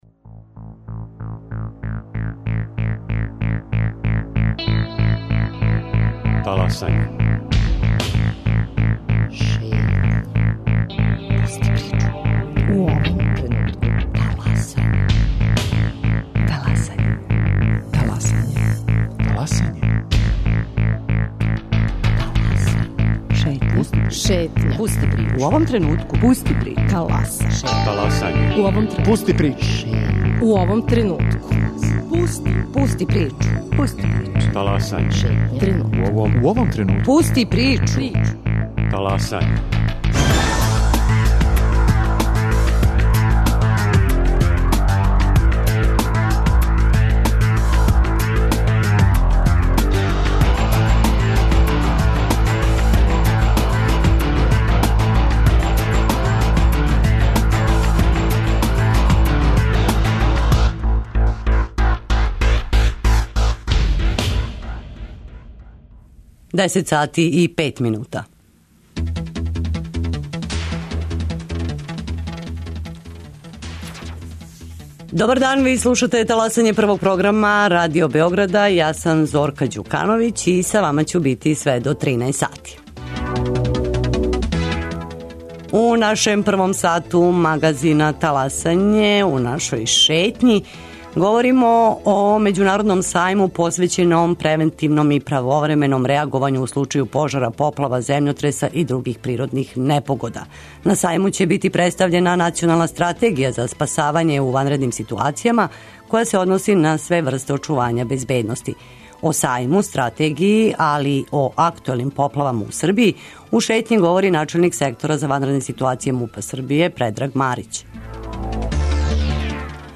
О сајму, стратегији, али и о актуелним поплавама у Србији, у Шетњи говори начелник сектора за ванредне ситуације МУП-а Србије Предраг Марић.